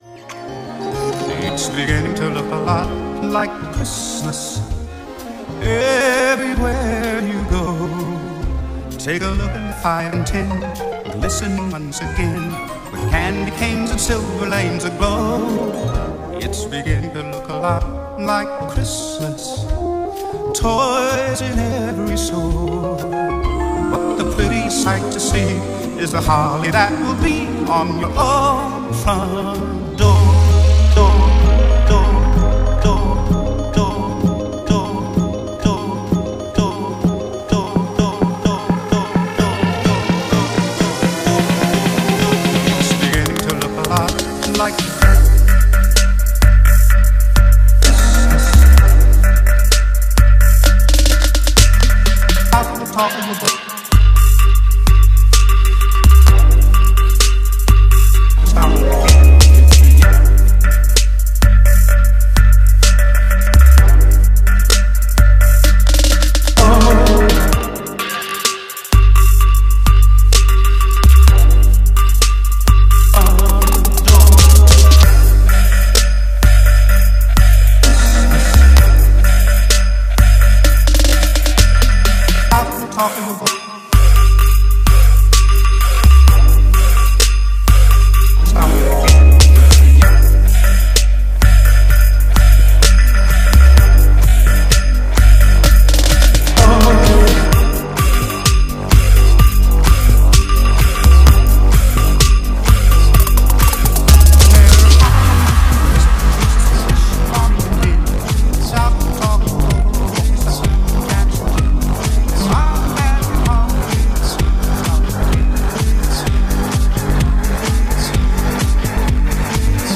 My Christmas mix from 2017.